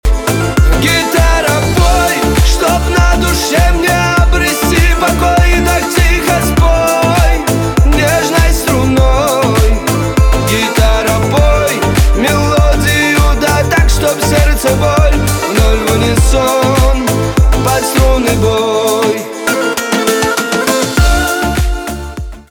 кавказские
битовые
чувственные